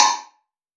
Snr Wood Pop.wav